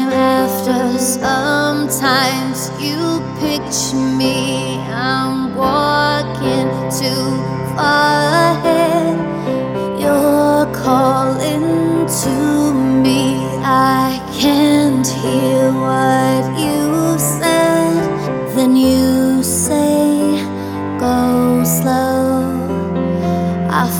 With Lead Vocals